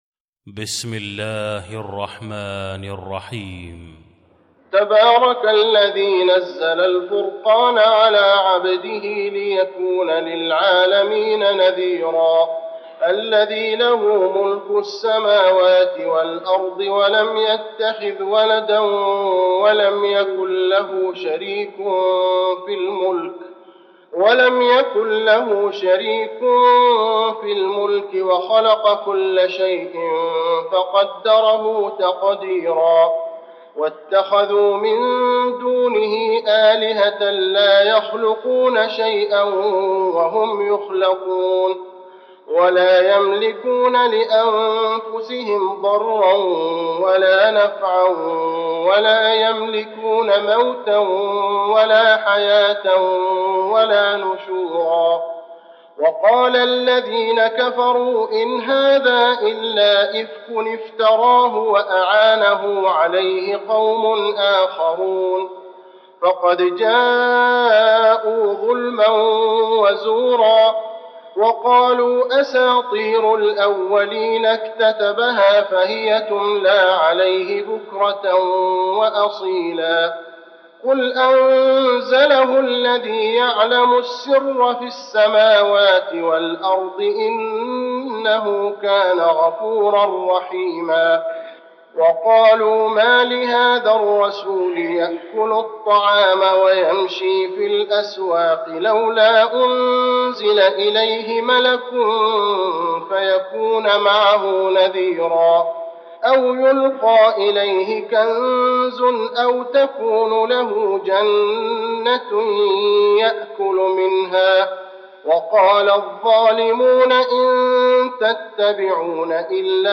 المكان: المسجد النبوي الفرقان